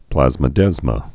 (plăzmə-dĕzmə) also plas·mo·desm (plăzmə-dĕzəm)